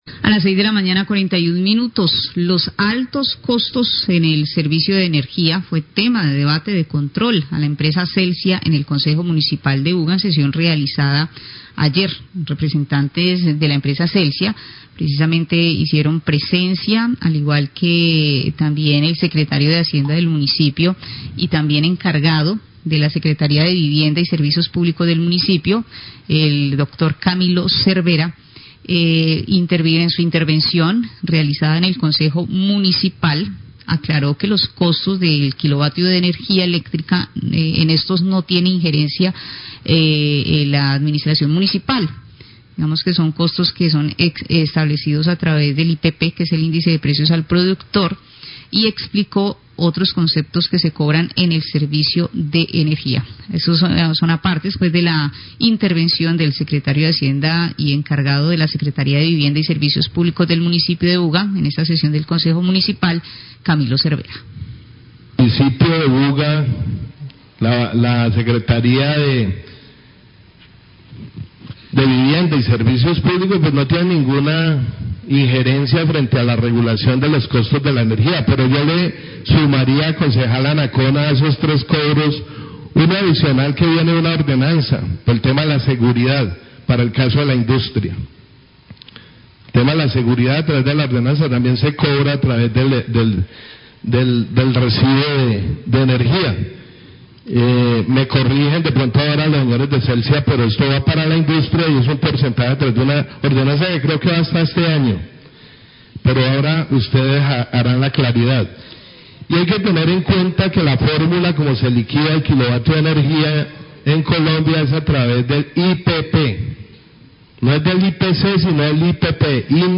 Srio Vivienda y SSPP de Buga habla sobre tarifas de energía en debate de control
El Secretario (E) de Vivienda y Servicios Públicos de Buga, Camilo Cervera, habla en el debate de control a Celsia por las altas tarifas de energía que realizó ayer el Concejo de Buga y de la formula cómo se establecen las tarifas.